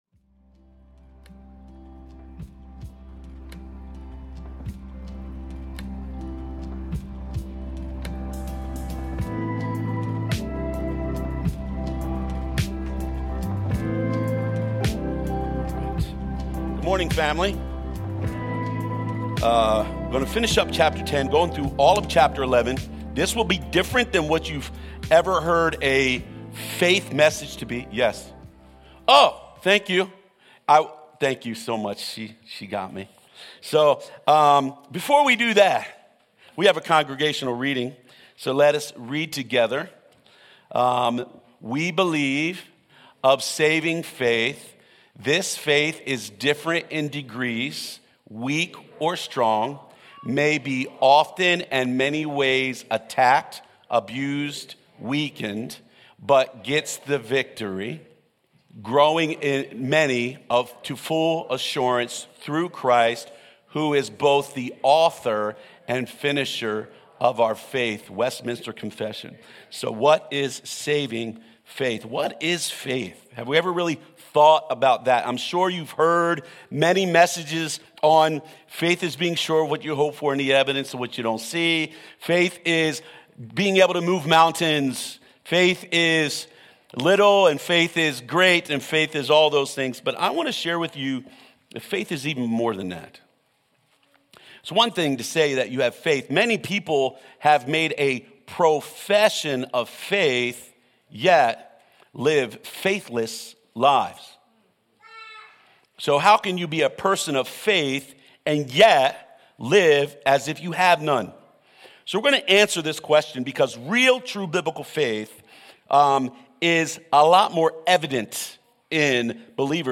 Sermons | Mount Eaton Church